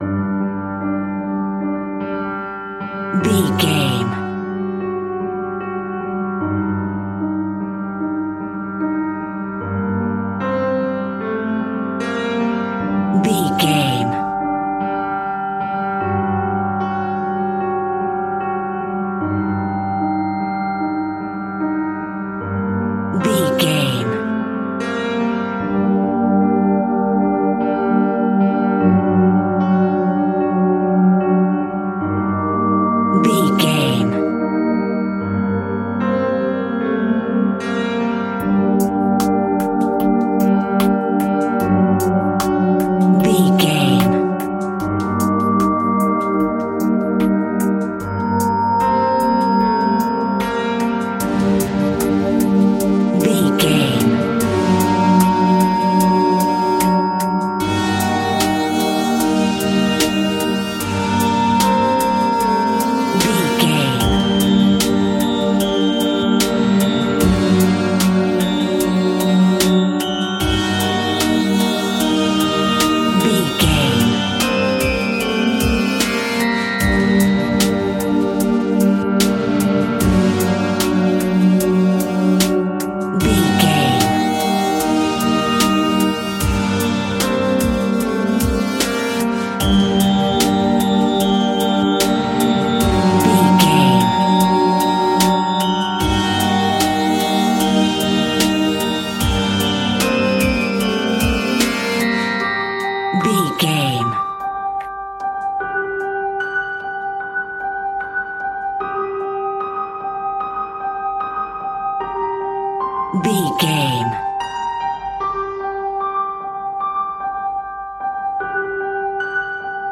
Music for Horror.
In-crescendo
Thriller
Aeolian/Minor
ominous
dark
haunting
eerie
melancholic
piano
synthesiser
drums